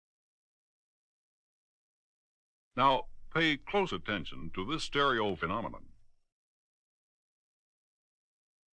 Positive dynamic tech-house album